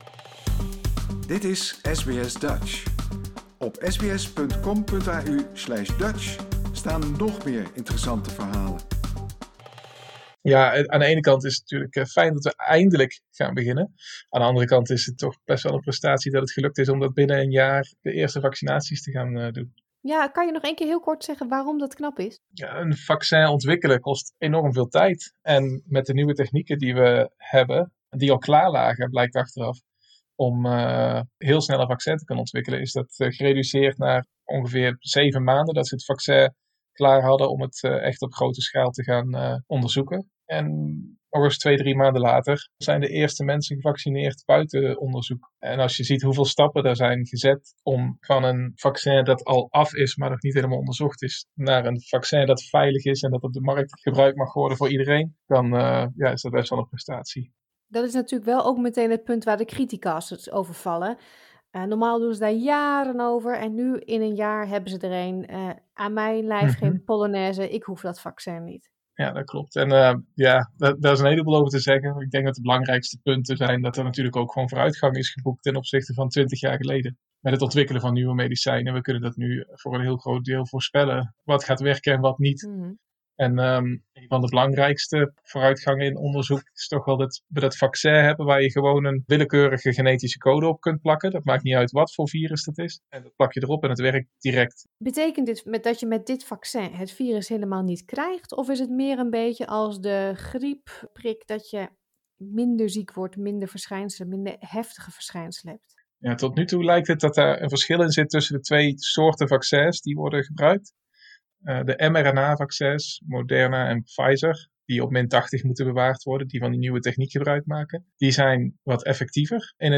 We vragen hem het hemd van het lijf over het Australische vaccinatieprogramma.